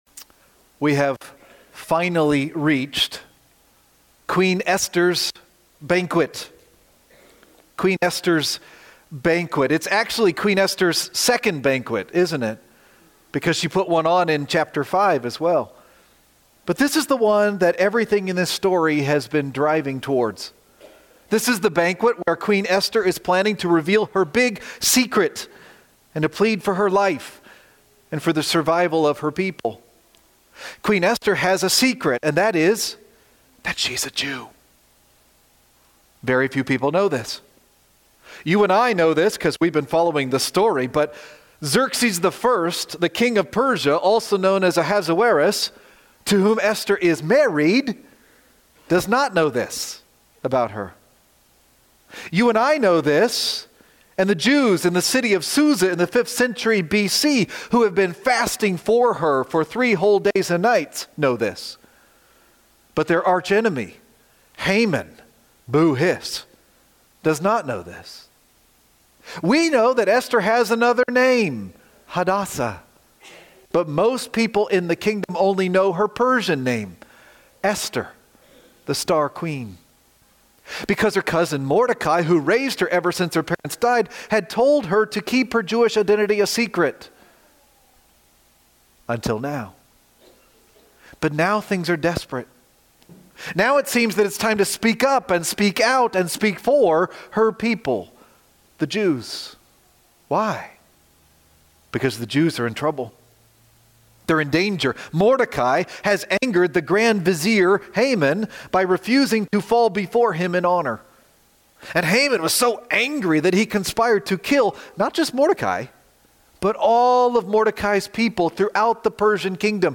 You can listen here or “Right-Click” and “Save-as” to download the sermon: “Queen Esther’s Banquet”– November 30, 2025